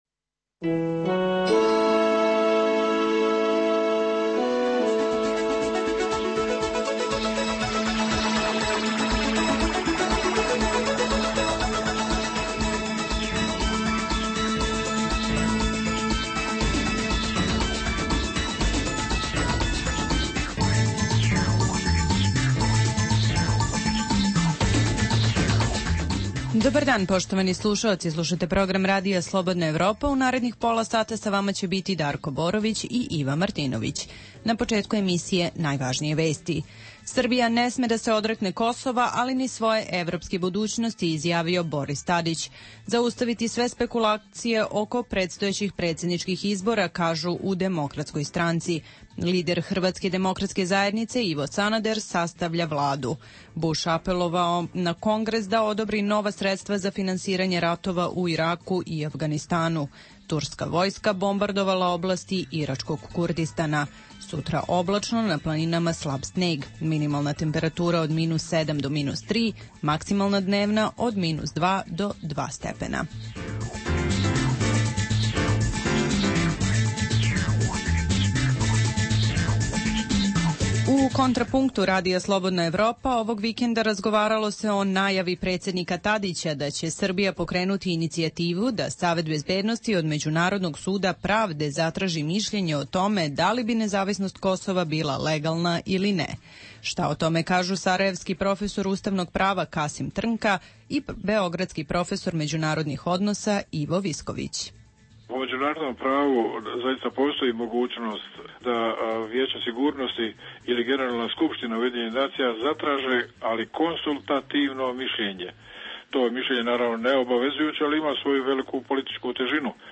U našem popodnevnom programu za Srbiju ćete saznati kako srpski zvaničnici reaguju na poruke sa samita EU u Briselu. Čućete i reportažu o tome kako Srbi u jednom selu u centralnom Kosovu vide mogućnost proglašenja nezavisnosti.